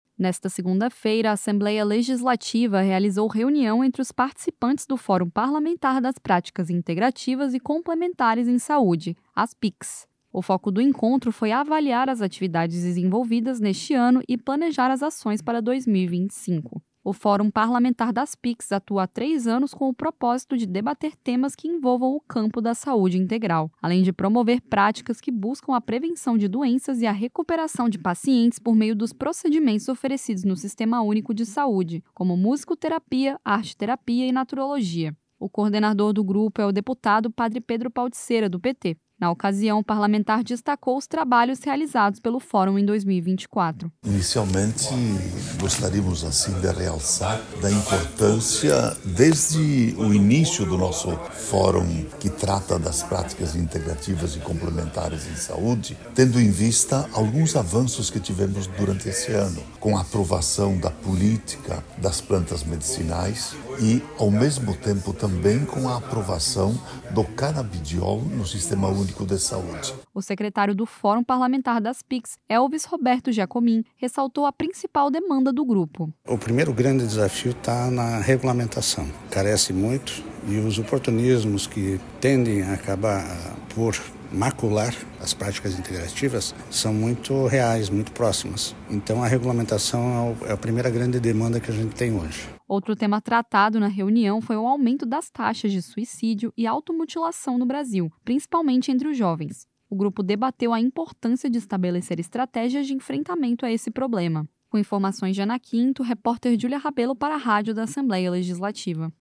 Entrevistas com:
- deputado Padre Pedro (PT), coordenador do Fórum Parlamentar das Práticas Integrativas e Complementares em Saúde (PICs);